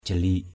/ʥa-li:ʔ/ 1.
jalik.mp3